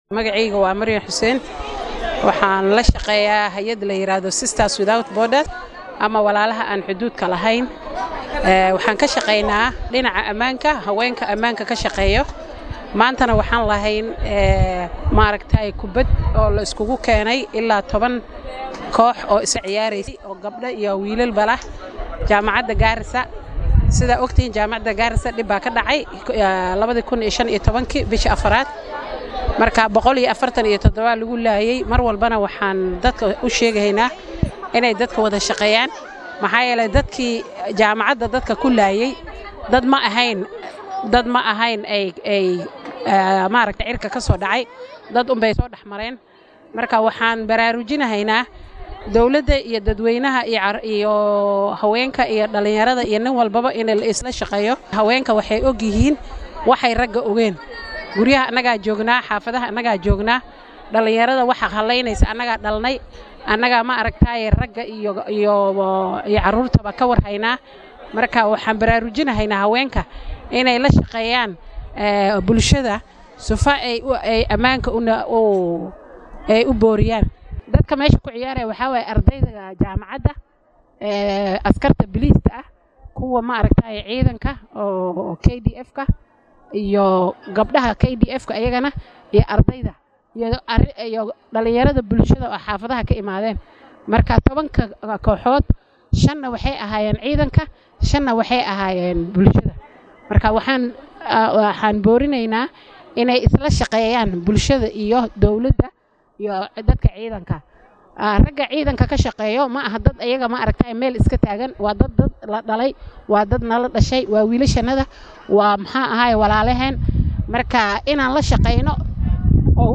Qaar ka mid ah dadkii soo qaban qaabiyey tartanka ayaa la hadlay warbaahinta Star iyagoo faahfaahin ka bixiyay ujeeddada ay ka lahaayeen. Sidoo kale qaar ka mid ah cayaartooyada ayaa dareenkooda la wadaagay warbaahinta.